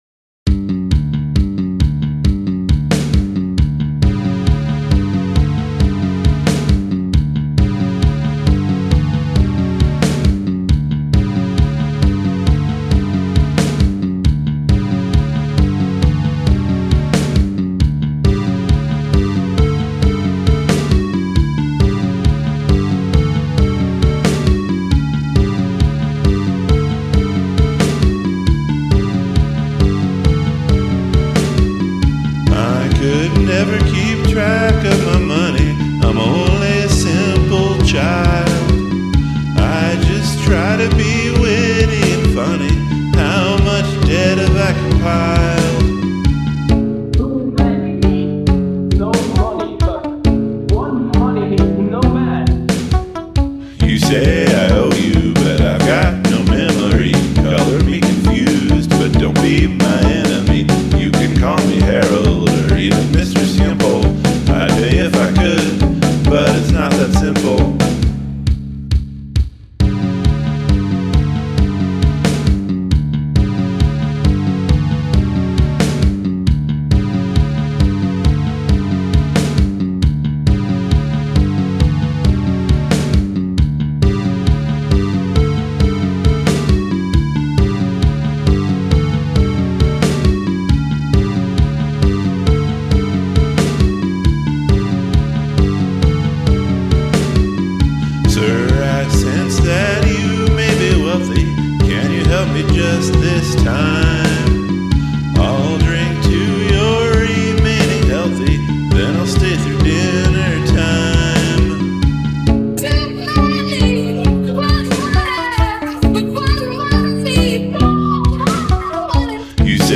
Elements of chiptune/ computer sounds